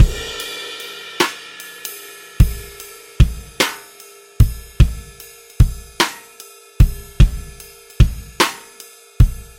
Tag: 75 bpm Jazz Loops Drum Loops 1.62 MB wav Key : Unknown Pro Tools